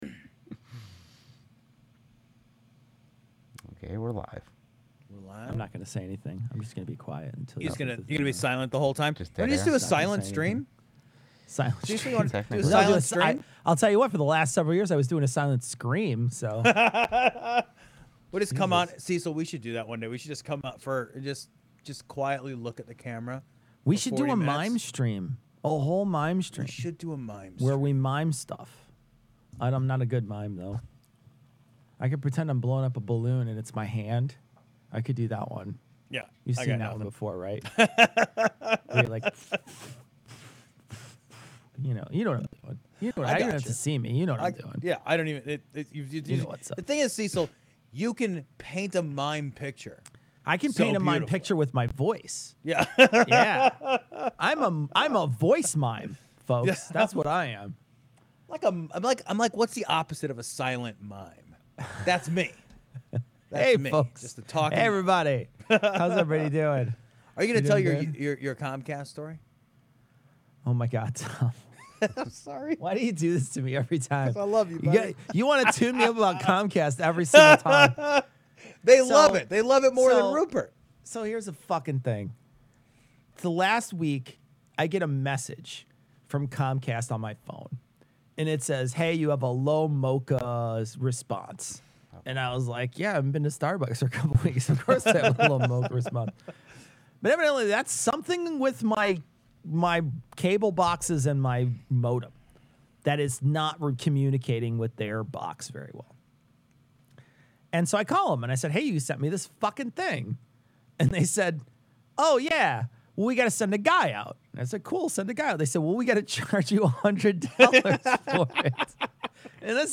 livestream